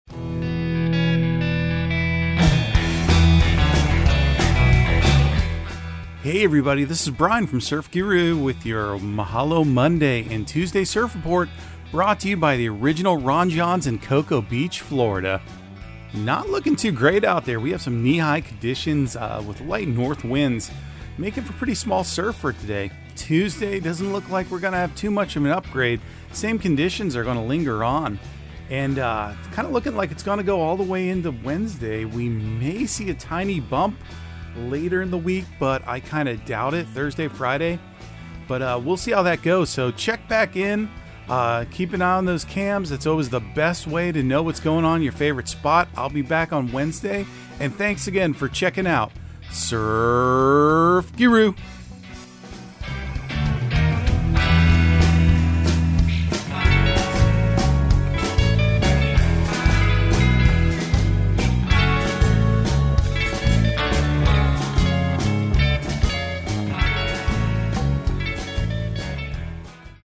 Surf Guru Surf Report and Forecast 01/09/2023 Audio surf report and surf forecast on January 09 for Central Florida and the Southeast.